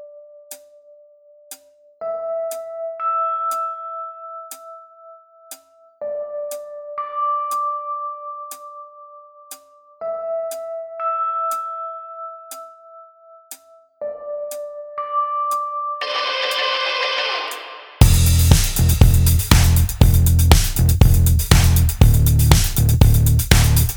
Minus All Guitars Pop (2000s) 4:10 Buy £1.50